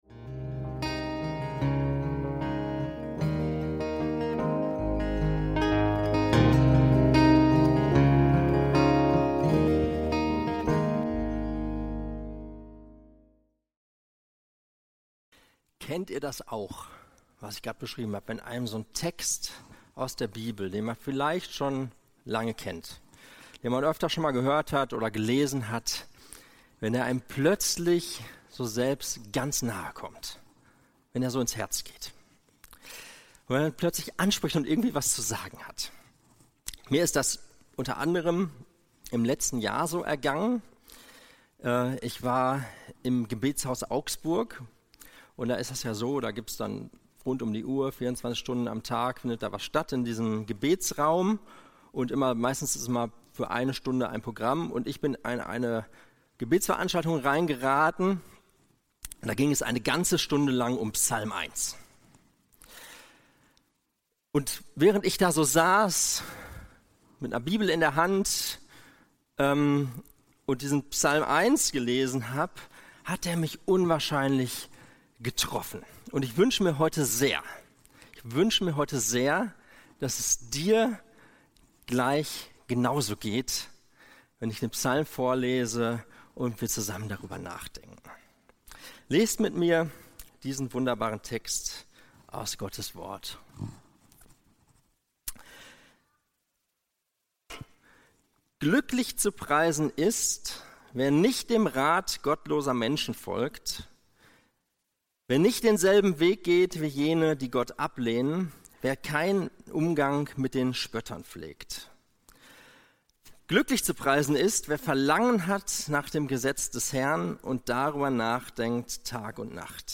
Wurzeln schlagen am Wort Gottes (Psalm 1) – Predigt vom 10.08.2025